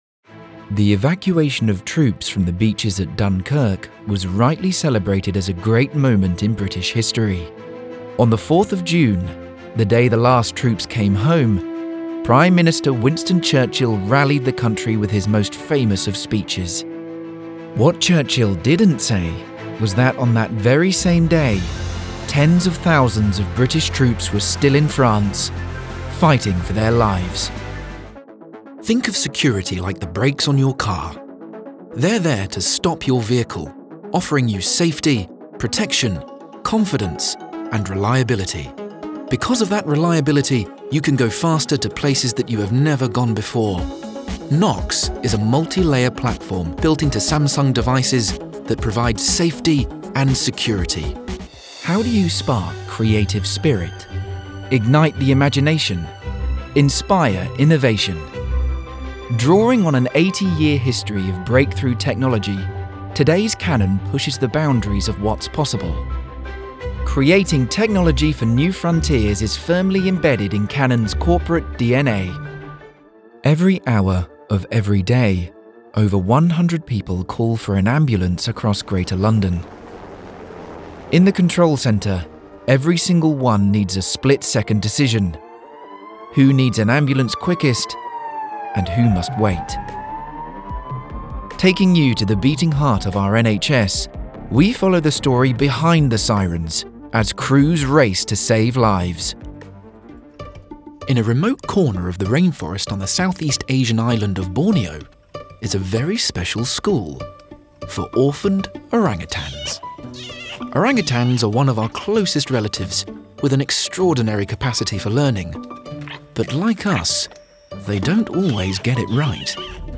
• Native Accent: RP, Welsh
• Home Studio